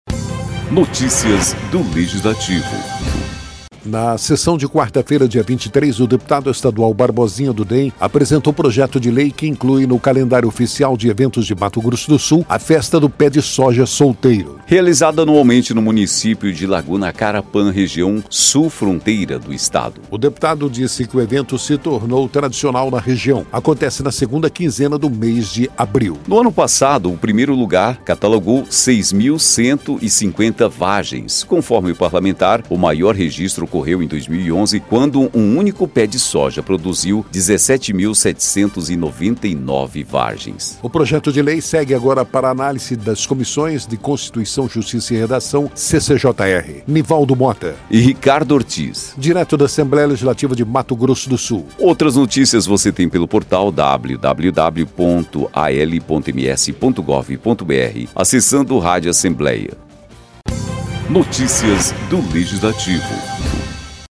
Fonte: Agência Rádio Assembleia